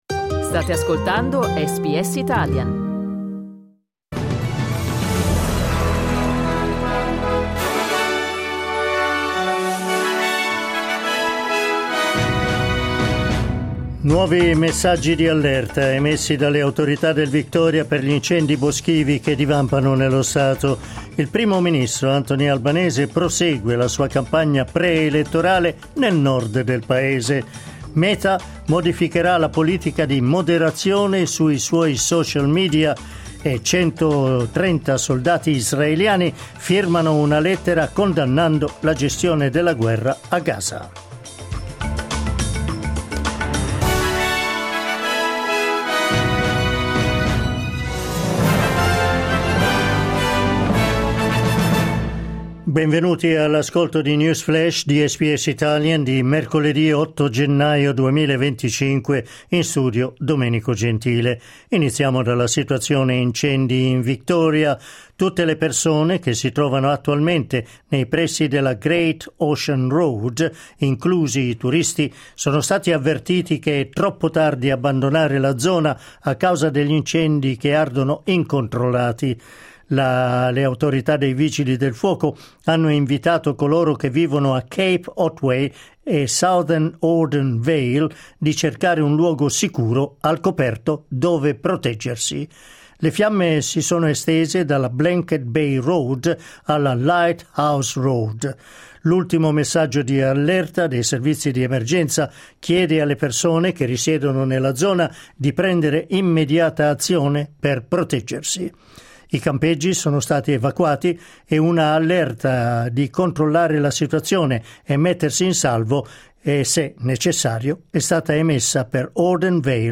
News flash mercoledì 8 gennaio 2025